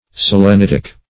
Search Result for " selenitic" : The Collaborative International Dictionary of English v.0.48: Selenitic \Sel`e*nit"ic\, Selenitical \Sel`e*nit"ic*al\, a. (Min.)